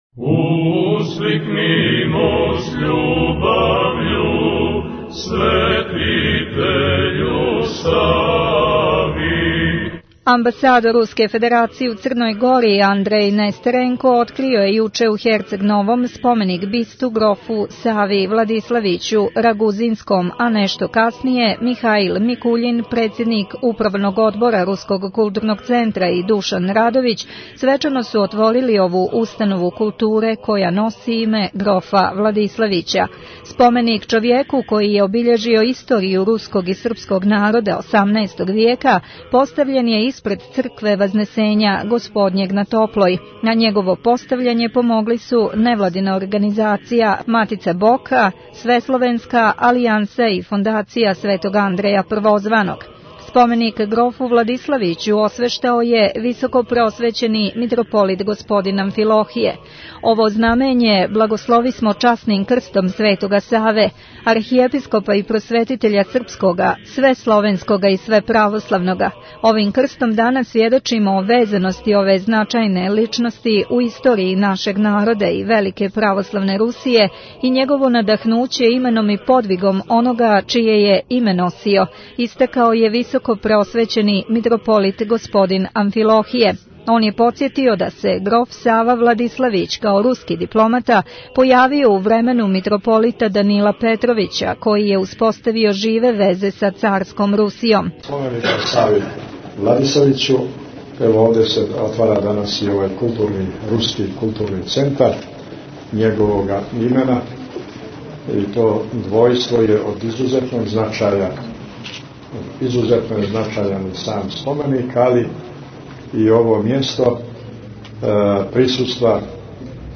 Извјештаји